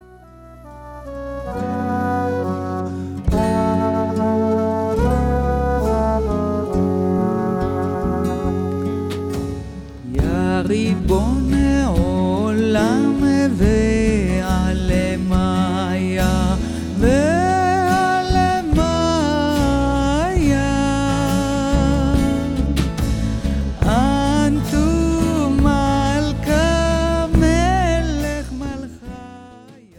In her warm, deep voice
Folk